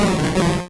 Cri de Psykokwak dans Pokémon Rouge et Bleu.